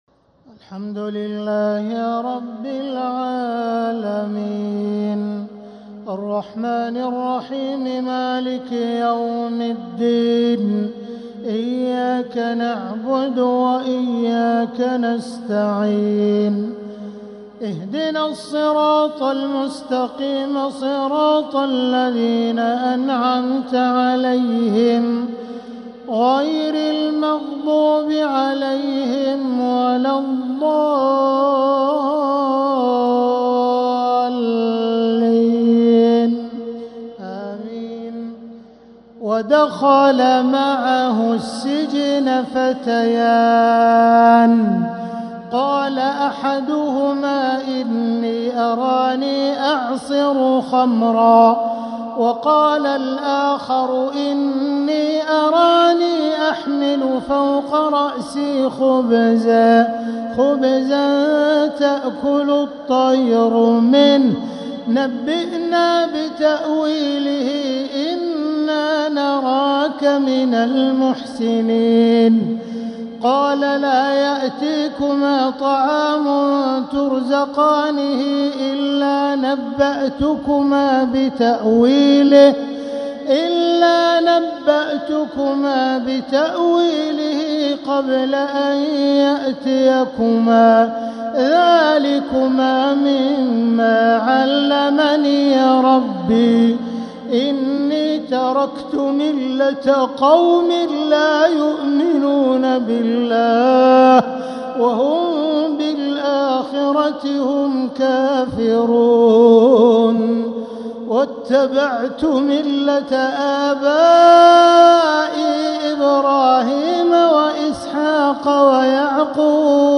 تراويح ليلة 16 رمضان 1446هـ من سورة يوسف {36-57} Taraweeh 16th night Ramadan 1446H Surah Yusuf > تراويح الحرم المكي عام 1446 🕋 > التراويح - تلاوات الحرمين